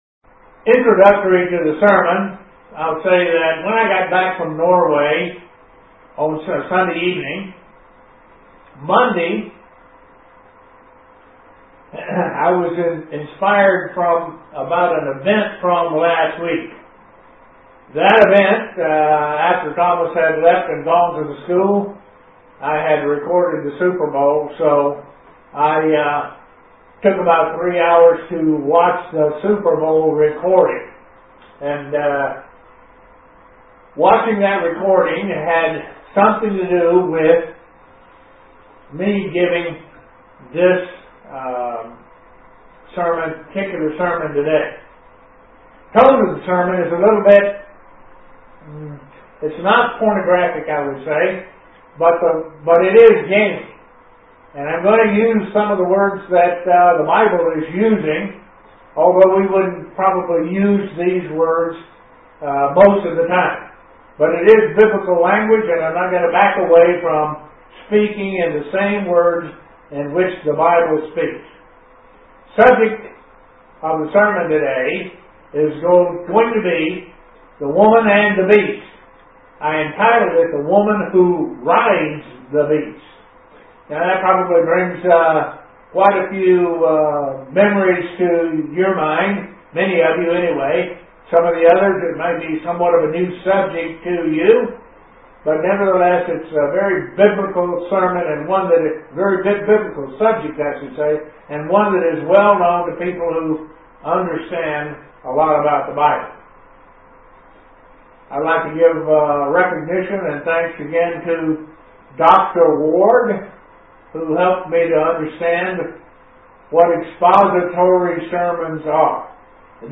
Given in Elmira, NY Buffalo, NY
UCG Sermon Studying the bible?